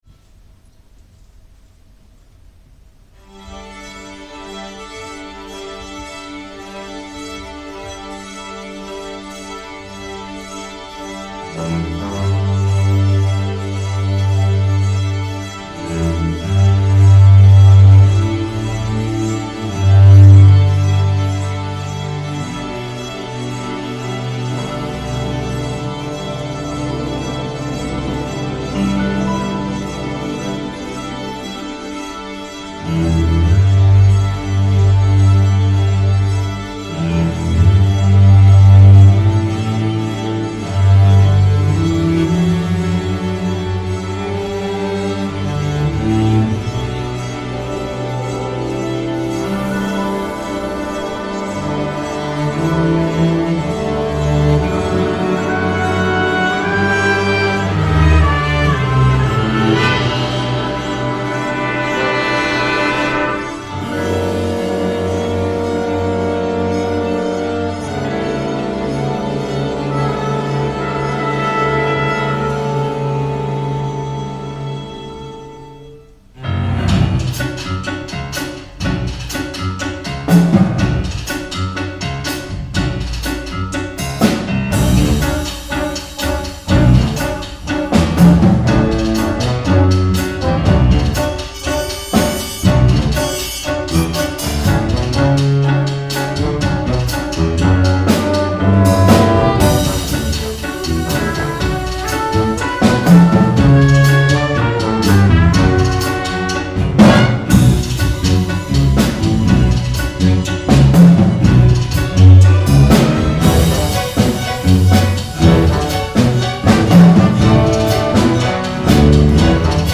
FOR BIG BAND and STRING ORCHESTRA